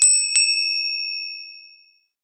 Ringtone.mp3